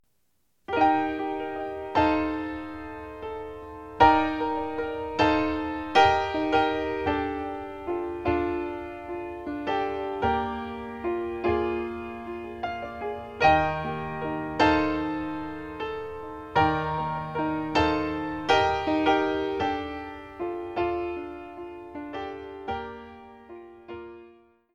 Tónica Mi♭